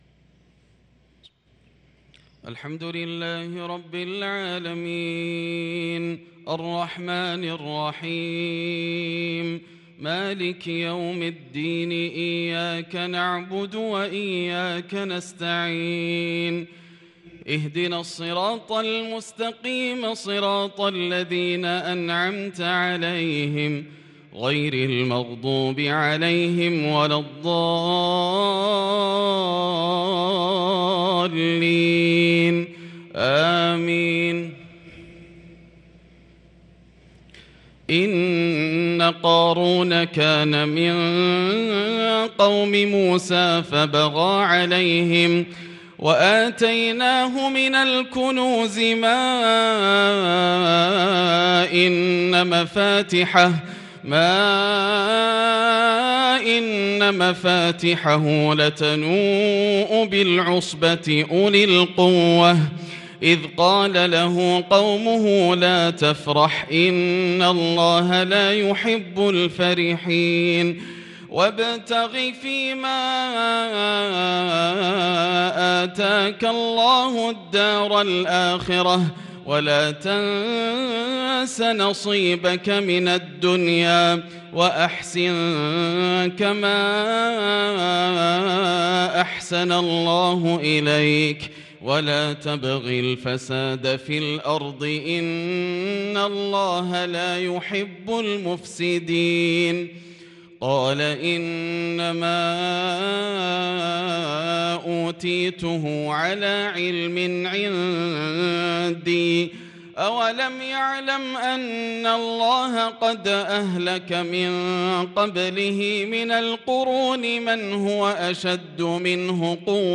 صلاة الفجر للقارئ ياسر الدوسري 18 شوال 1443 هـ